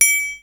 METALLIC.wav